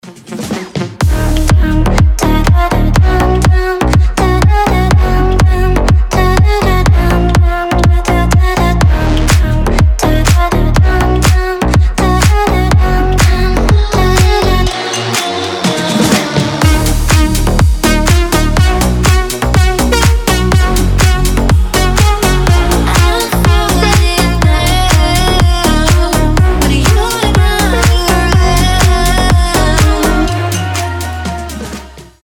• Качество: 320, Stereo
EDM
future house
басы
slap house
Сочный звук клубной нарезки